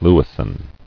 [lew·is·son]